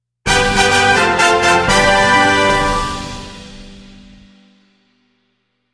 Вступительные фанфары